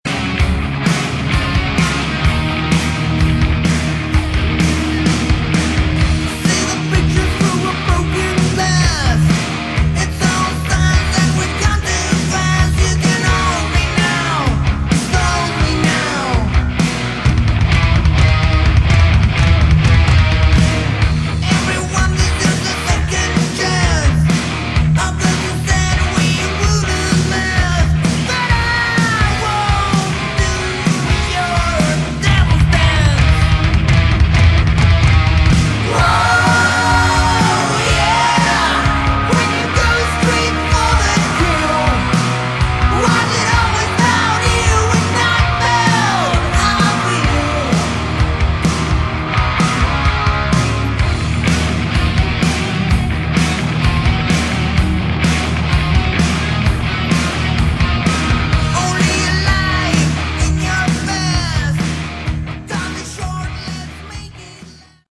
Category: Sleazy Hard Rock
vocals
guitars
drums
bass